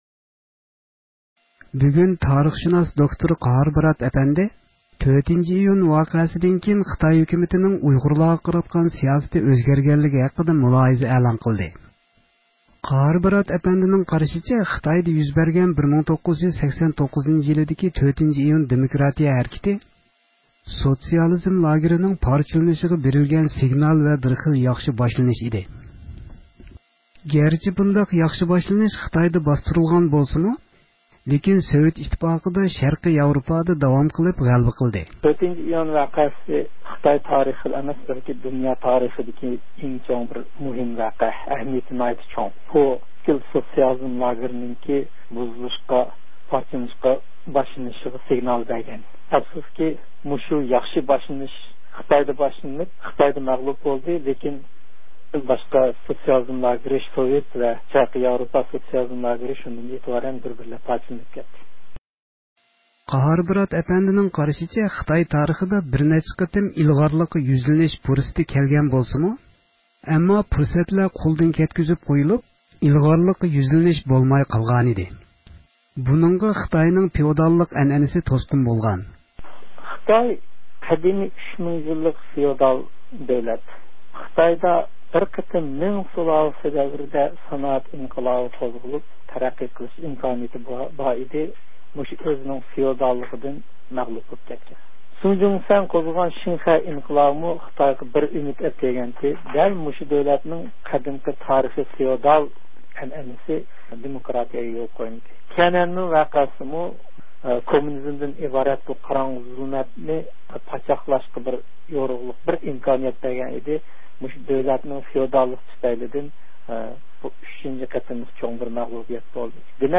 رابىيە خانىم بىلەن ئۆتكۈزگەن سۆھبىتىنىڭ تەپسىلاتىدىن خەۋەر ئالغايسىز